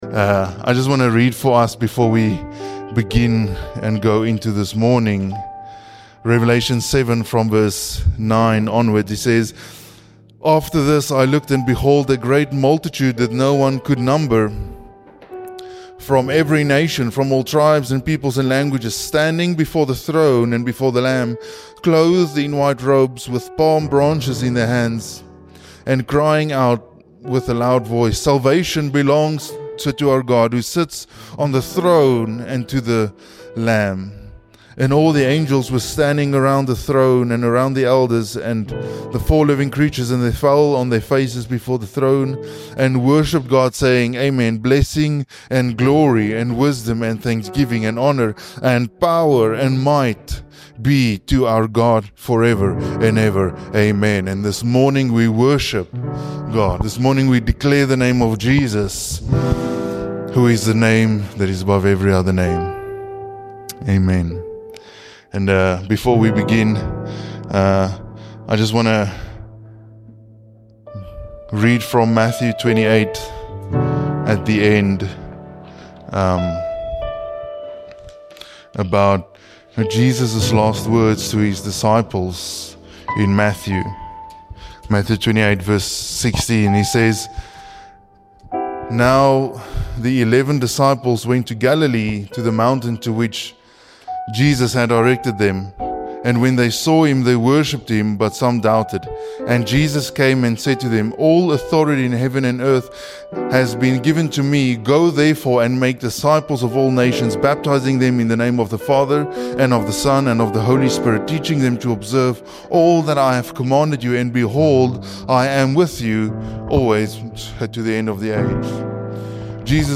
GBC Podcast to share audio sermons and talks.